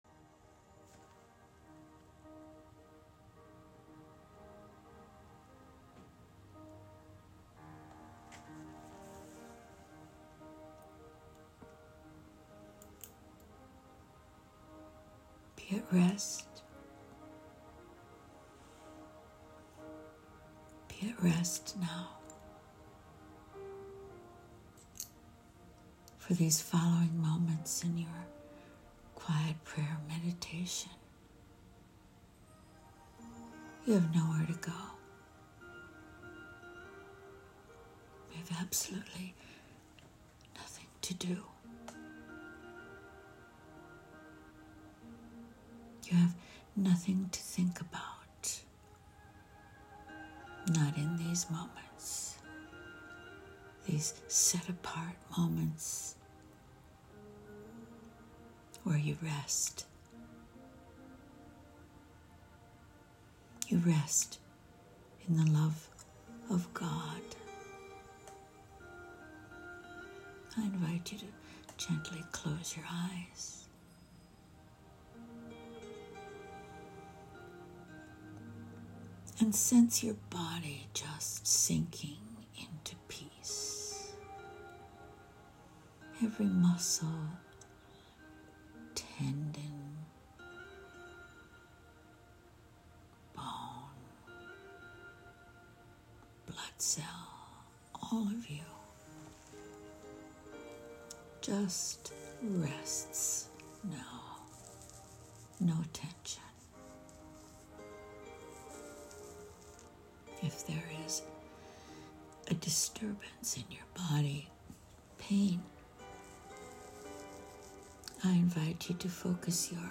Christian Meditation